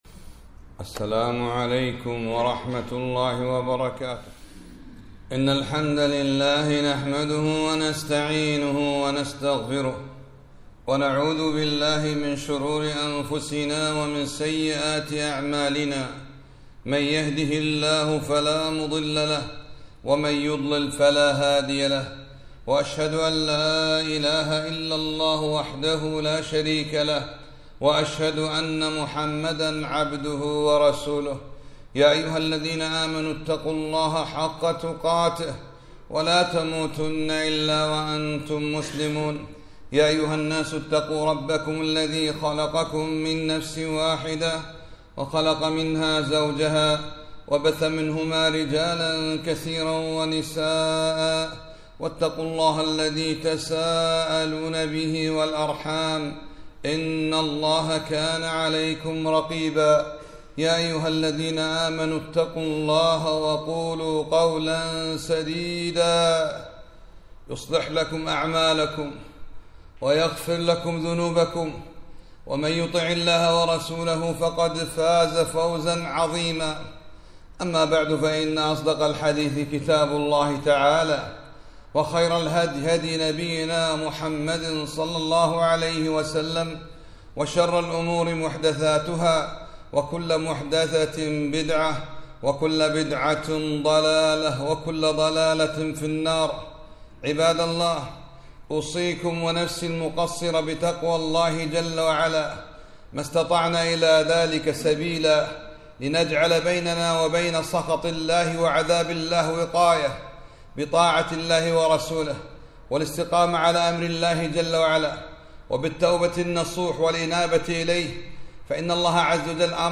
خطبة -